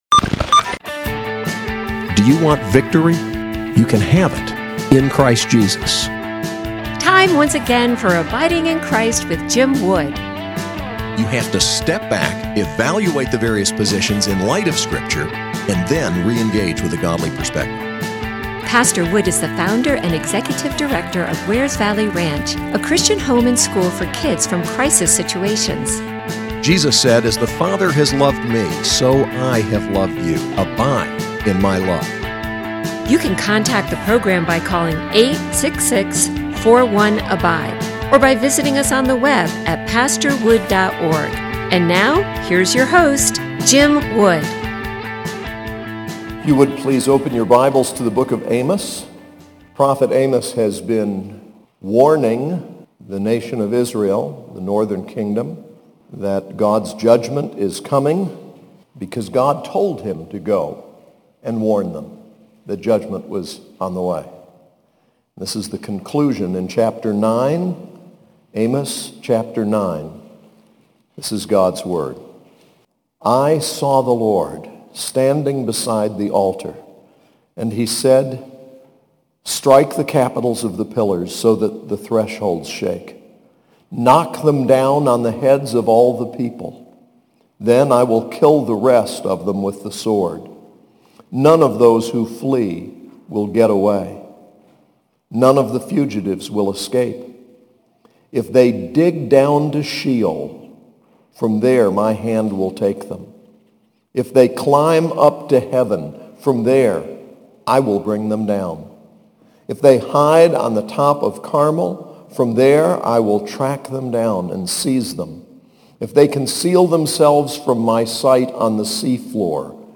SAS Chapel: Amos 9